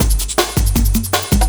06 LOOP11 -R.wav